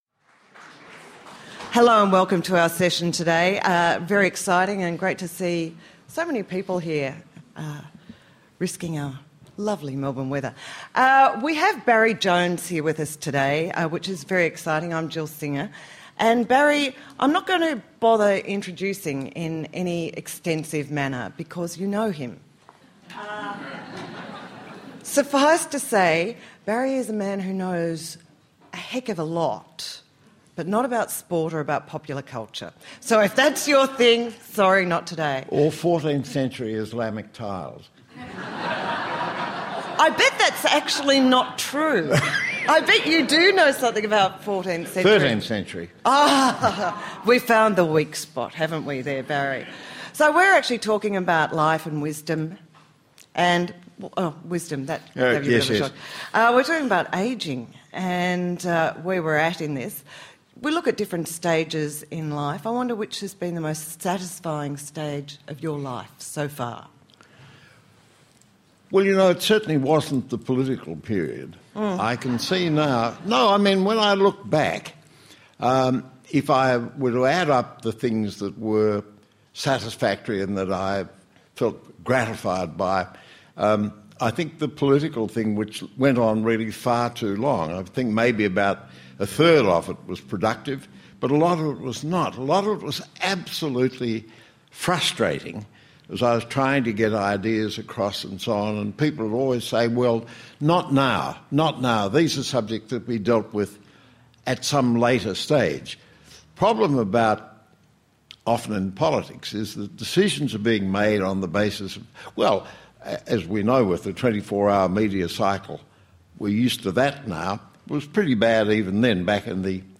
Join host Jill Singer for a conversation with one of the nation’s true polymaths.